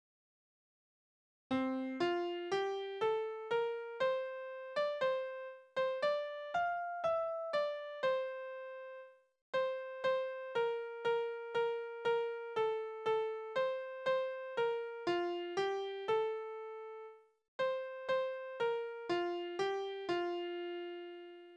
Liebeslieder:
Tonart: F-Dur
Taktart: 4/4
Tonumfang: Oktave, Quarte
Besetzung: vokal